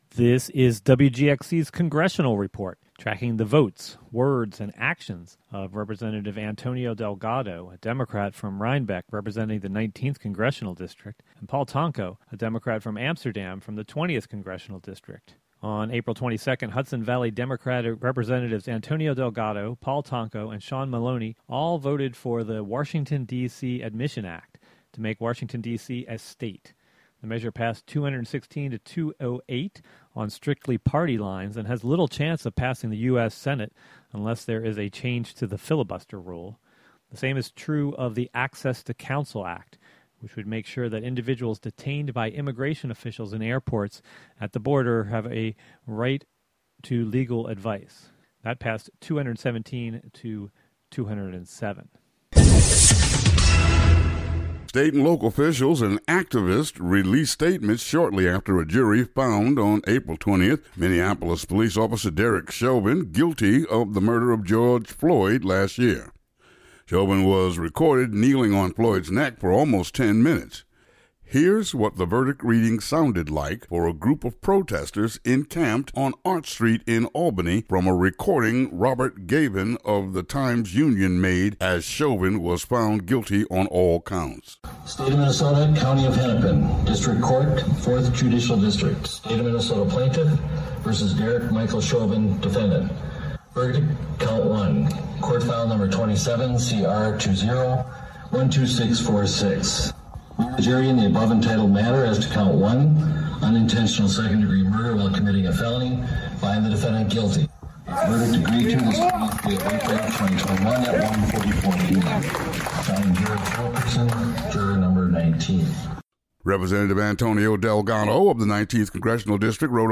Audio Feature: WGXC Congressional Report